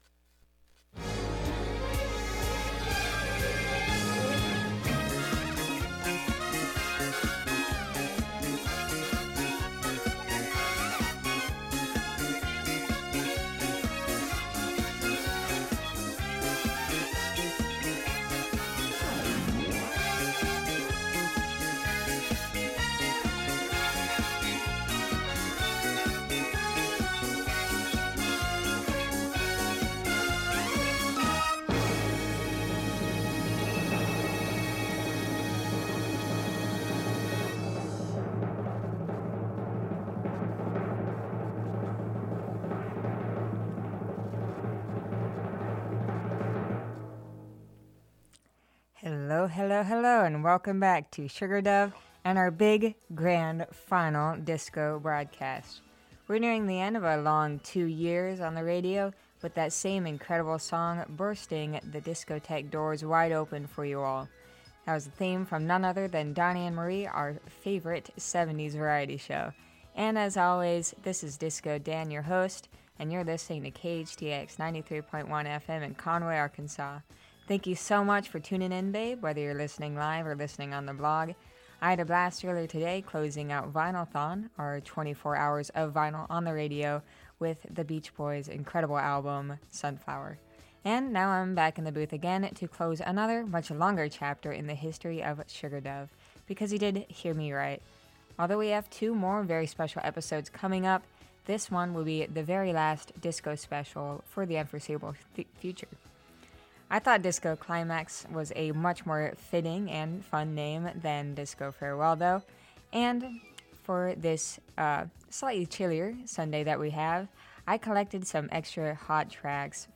Tonight marks our first show post-vinylthon and the end of an era: our very last disco show… but the music lives on in our hearts, and next week we’ll have a special episode looking back on the evolution of Sugar Dove!